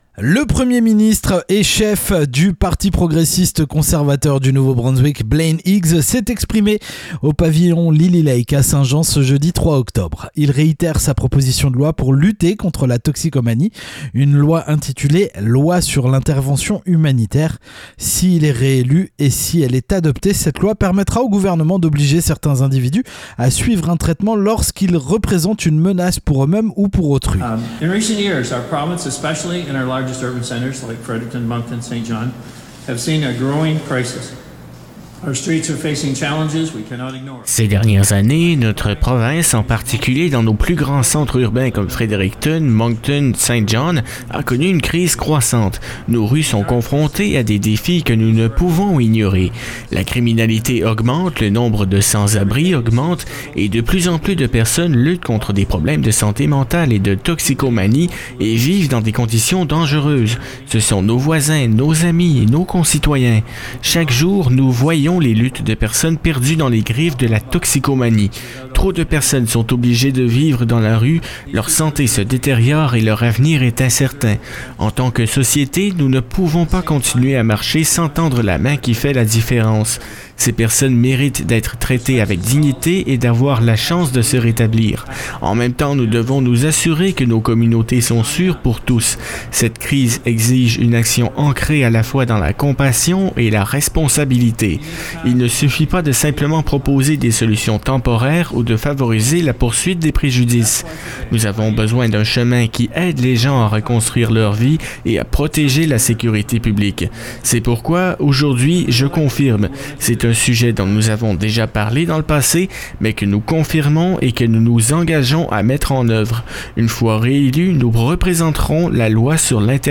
Blaine Higgs qui parle de son projet de loi, le 3 octobre dernier à Saint-Jean, au Pavillon du Lilly Lake.